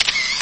SFX手机按快门的声音音效下载
SFX音效